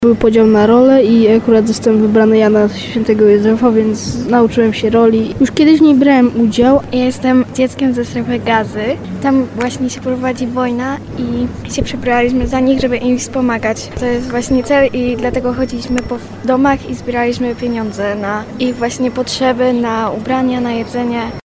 Dzieci, które tam przyjechały mówiły nam jak ważne jest pomaganie.